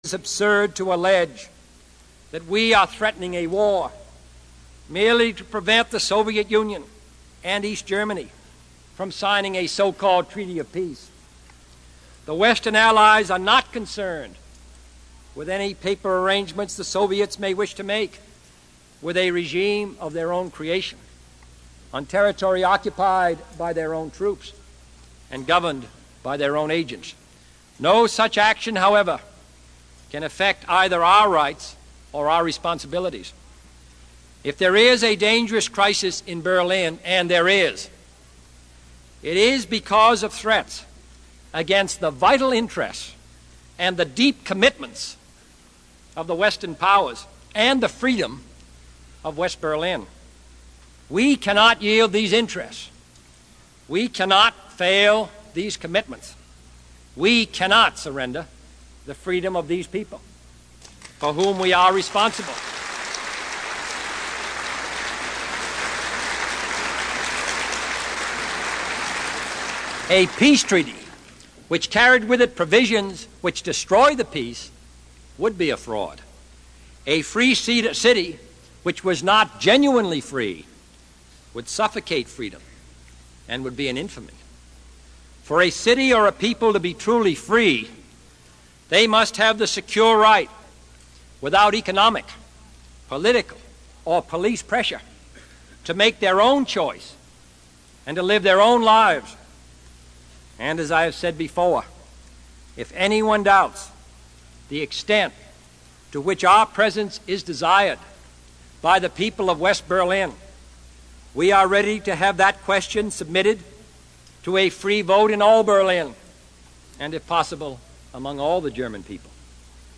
Tags: John F. Kennedy John F. Kennedy Address United Nations John F. Kennedy speech President